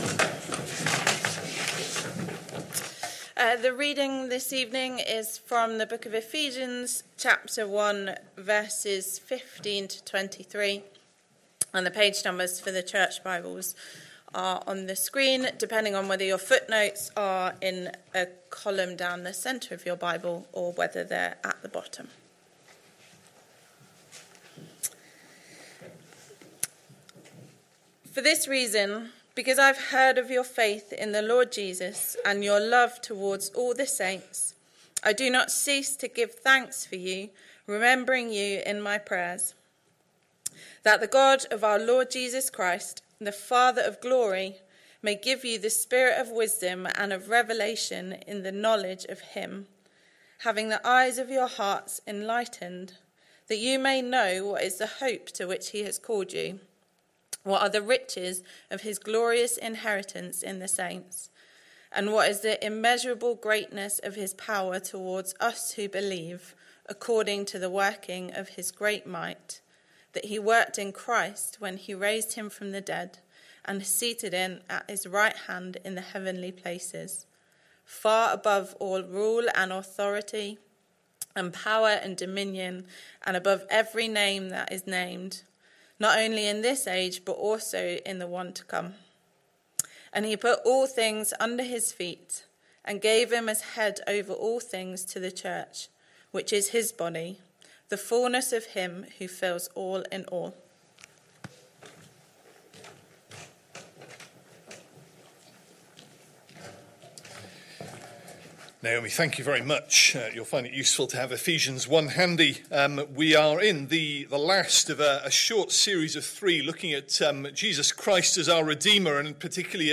Christ Church Sermon Archive